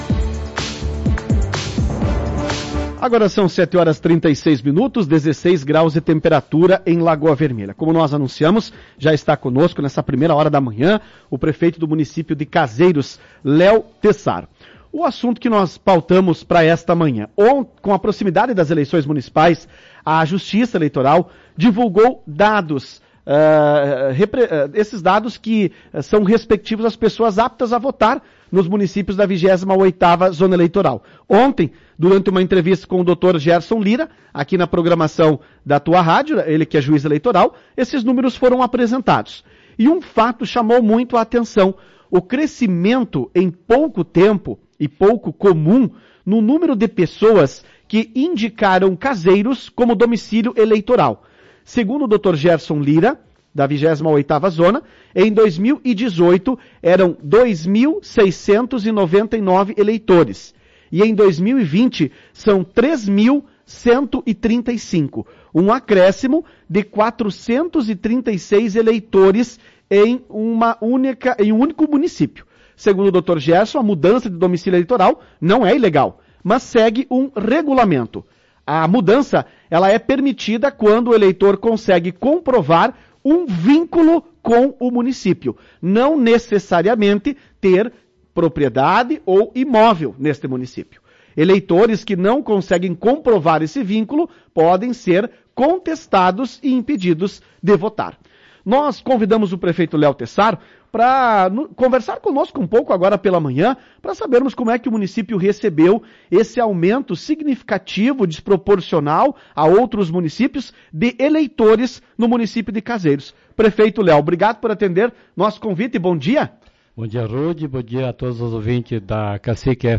Em entrevista à Tua Rádio Cacique, o prefeito de Caseiros, Leo Tessaro, falou sobre o fato incomum.